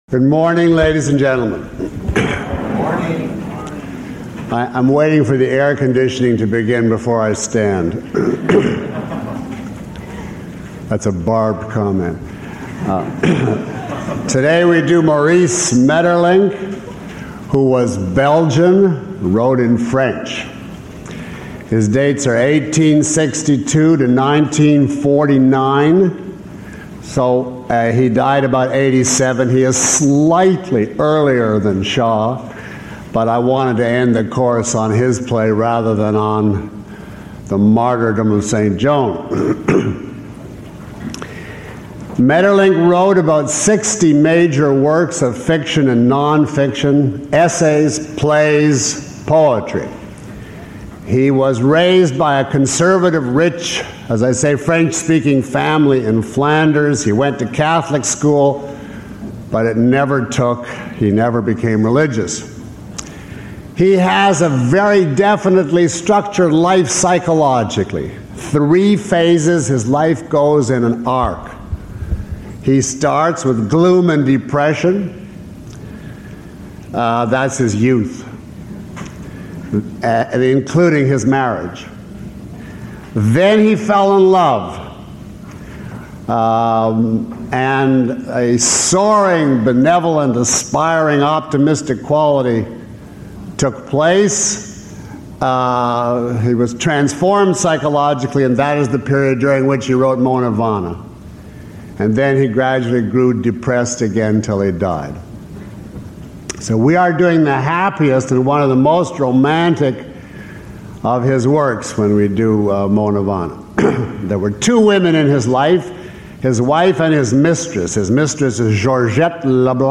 Lecture (MP3) Full Course (ZIP) Lecture Six Course Home Lecture Eight Questions about this audio?
Below is a list of questions from the audience taken from this lecture, along with (approximate) time stamps.